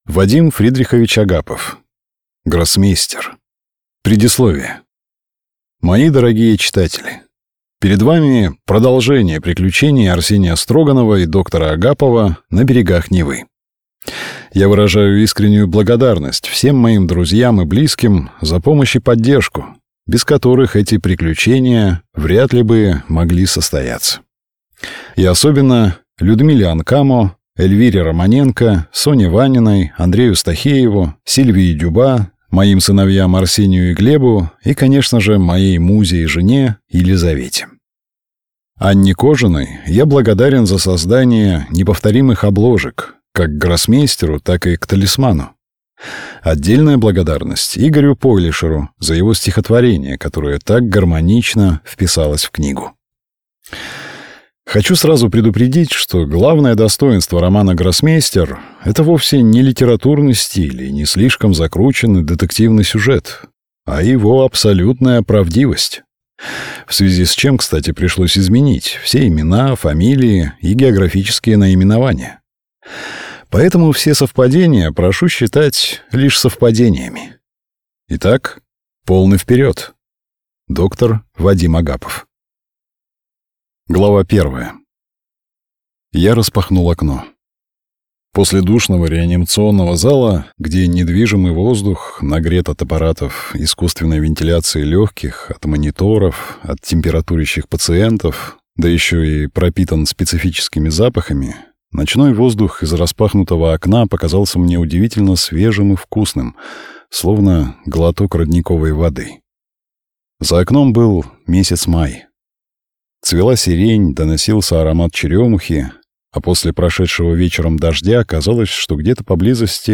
Аудиокнига Гроссмейстер | Библиотека аудиокниг